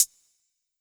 Closed Hats
BWB UPGRADE3 Hi-HAT (21).wav